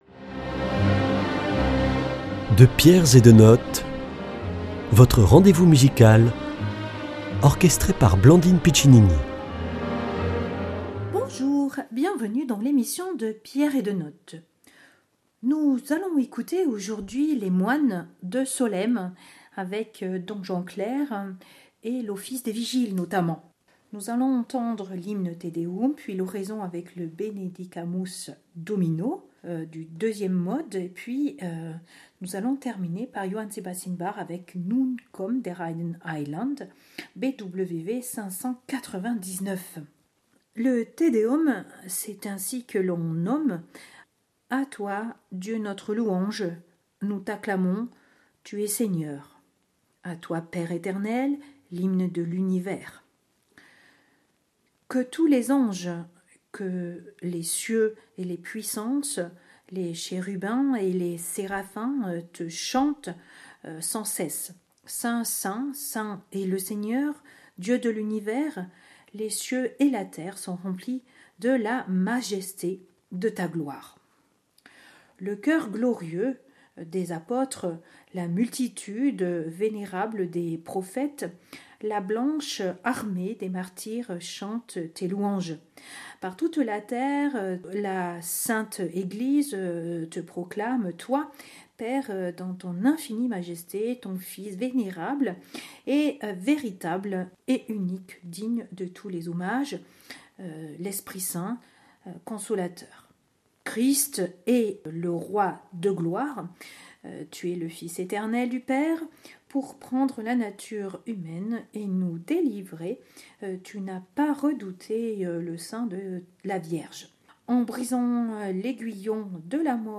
Il s’agit de redécouvrir l’hymne du te deum puis l’oraison et le benedicatmus Domino du second mode. Interprétés par le coeur des moines de l’abbaye de Saint Pierre de Solesme
grand orgue